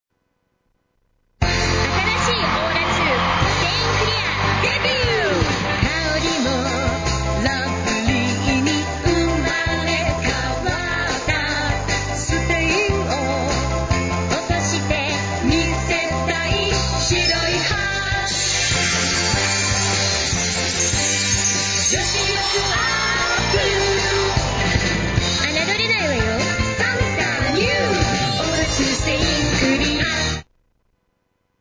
英語ナレーターボイスサンプル